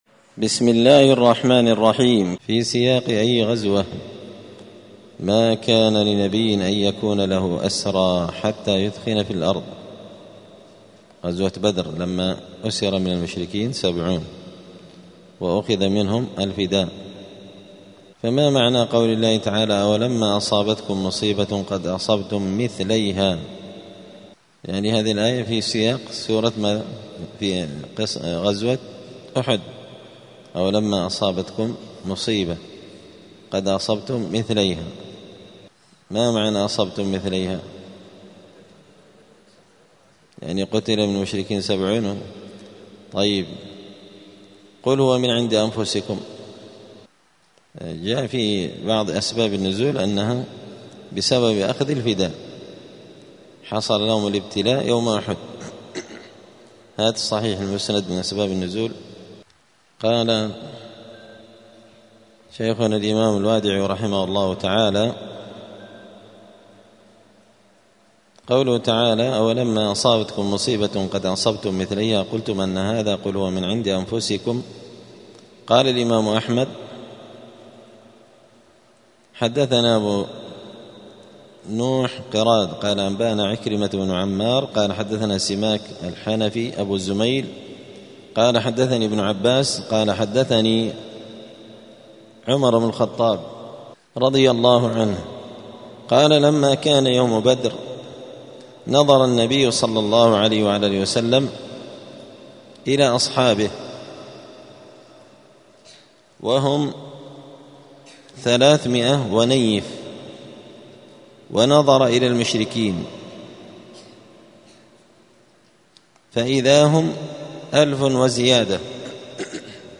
📌الدروس اليومية
دار الحديث السلفية بمسجد الفرقان قشن المهرة اليمن